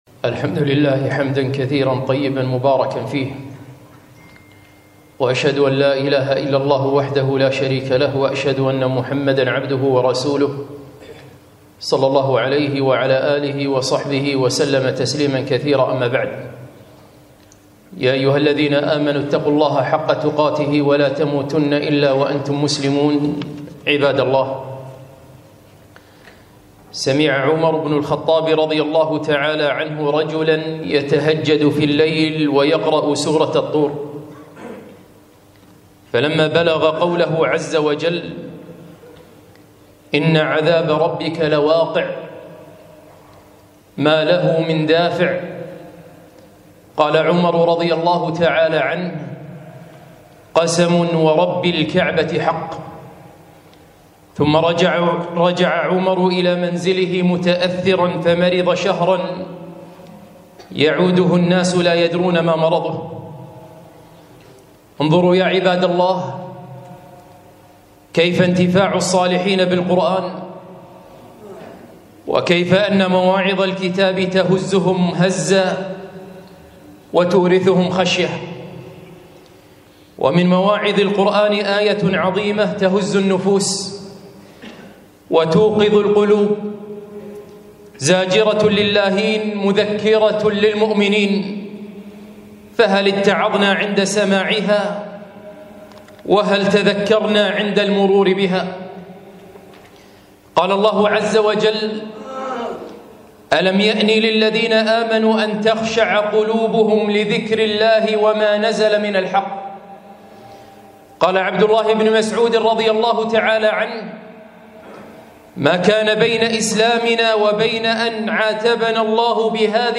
خطبة - هلا تذكرنا قبل دخول رمضان؟!